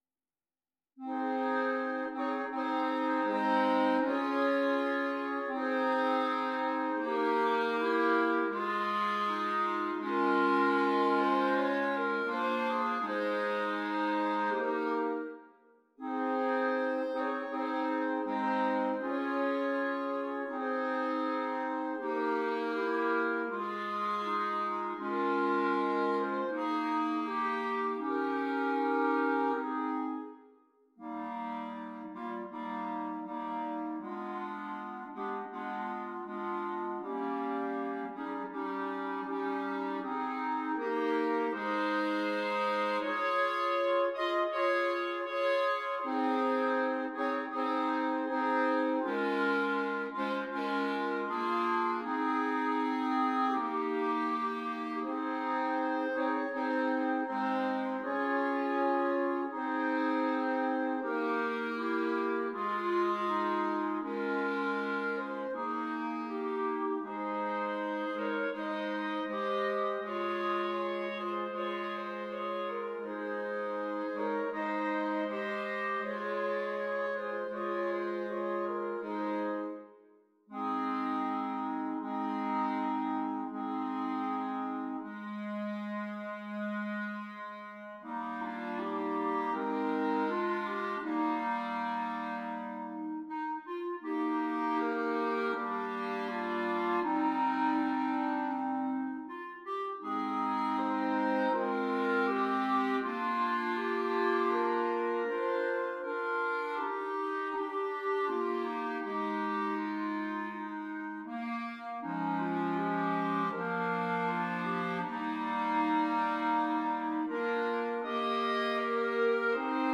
4 Clarinets